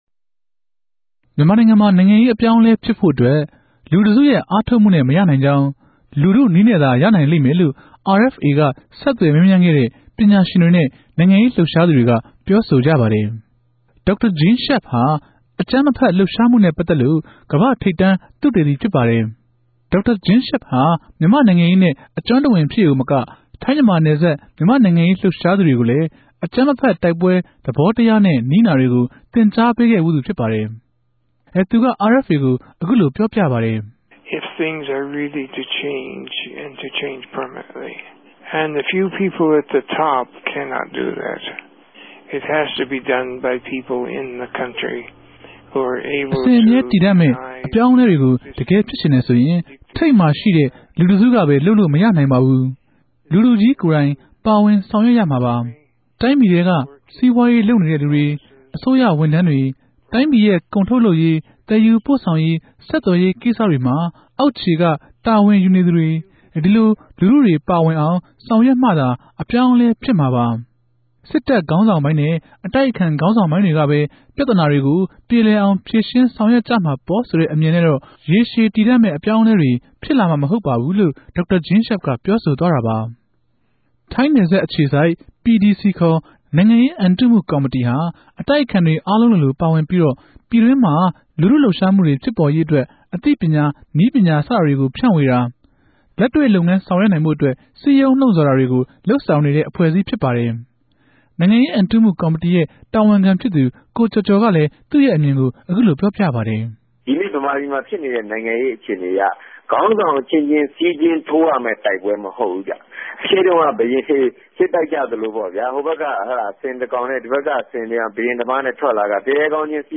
"ူမန်မာ့ိံိုင်ငံရေး႟ြထောင့်အထြေထြေ" အစီအစဉ်မြာ ူမန်မာူပည်ရဲ့ ိံိုင်ငံရေး ူင်္ပံနာကို ဘယ်သူတေကြ ေူဖရြင်းုကမလဲဆိုတာကို ဆြေးေိံြး တင်ူပထားပၝတယ်။ ရေရြည်တည်တံ့တဲ့ ဒီမိုကရေစီ အေူပာင်းအလဲ ူဖစ်ပေၞလာဖိုႛအတြက် ဘာတေကြို ဘယ်လိုလုပ်ုကမလဲ ဆိုတာကို ပညာရြင်တြေ၊ ိံိုင်ငံရေးလြပ်ရြားသူတေကြို RFA က ဆက်သြယ် မေးူမန်းထားပၝတယ်။